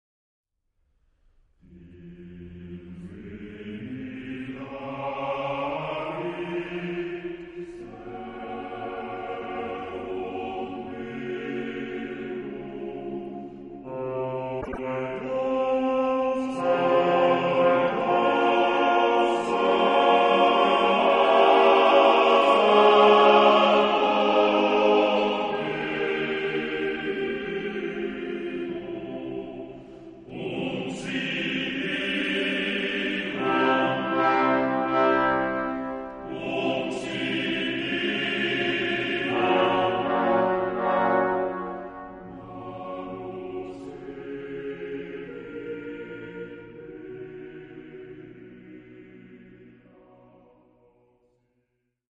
Genre-Style-Form: Romantic ; Sacred ; Motet
Type of Choir: TTBB  (4 men voices )
Instruments: Trombone (4)
Tonality: F minor
sung by NDR Chor Hamburg conducted by Hans-Christoph Rademann